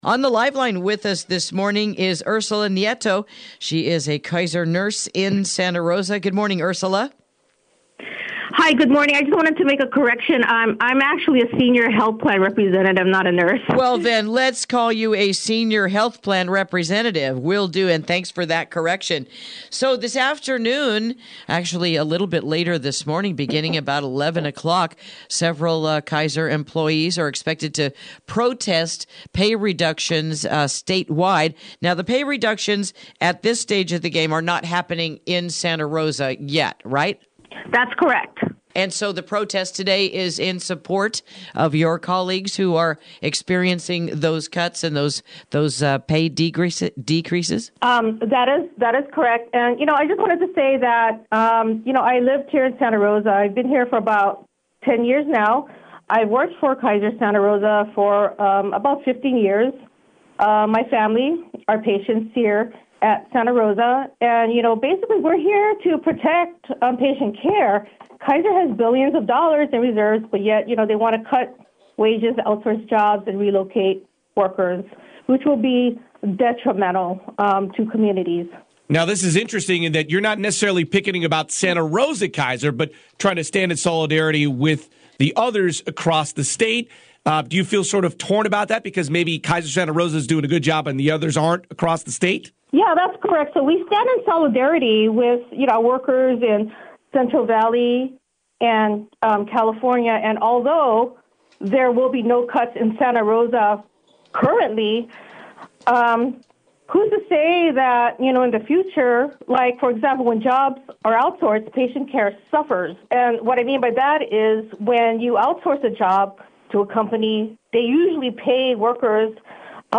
Interview: Why Are Kaiser Nurses Picketing Across California?